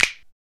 PRC SNAPS 0E.wav